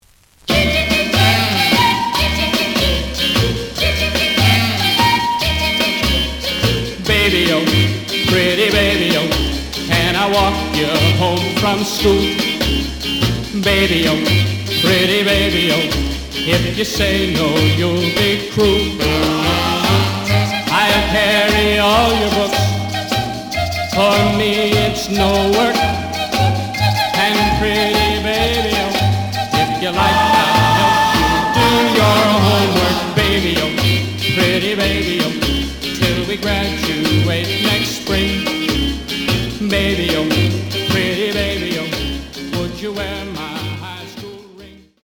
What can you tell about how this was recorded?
The audio sample is recorded from the actual item. Some damage on both side labels. Plays good.)